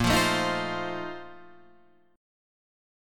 A#M7sus2sus4 chord {6 8 7 8 6 8} chord